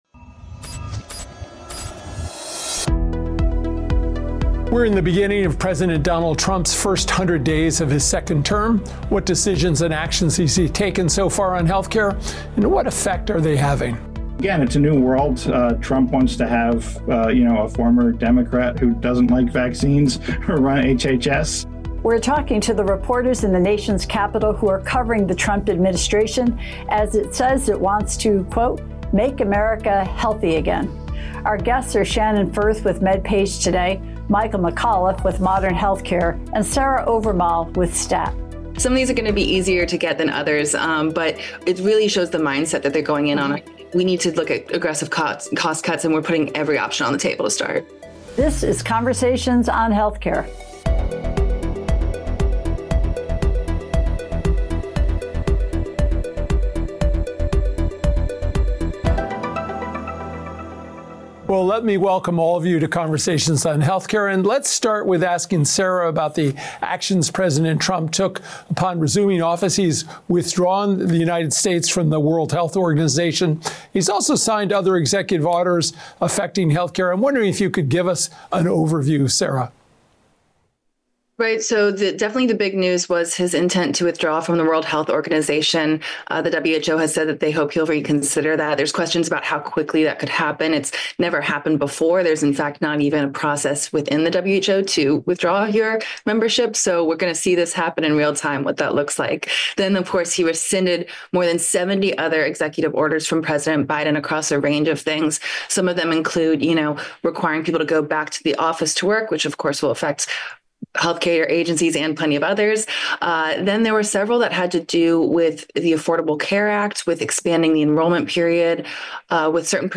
are bringing together leading healthcare journalists to discuss the new administration’s moves and what they mean.